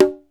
CONGA_DR.WAV